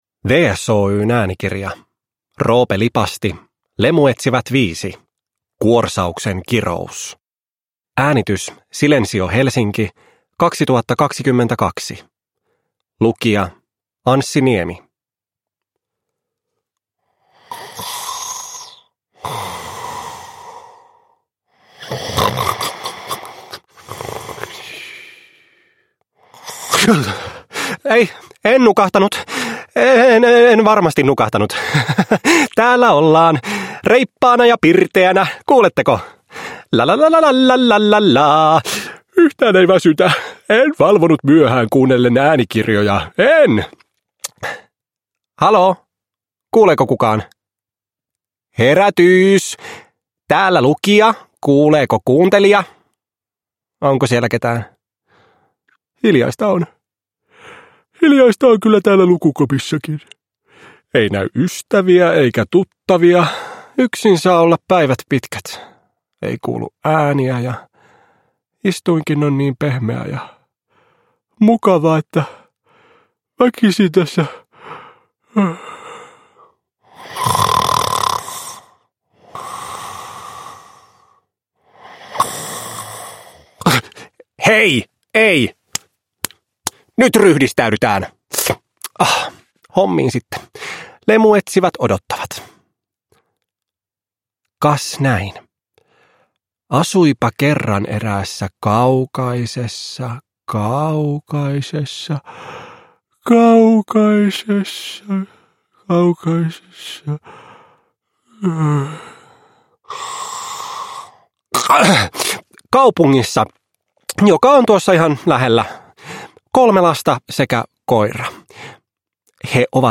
Lemuetsivät 5: Kuorsauksen kirous – Ljudbok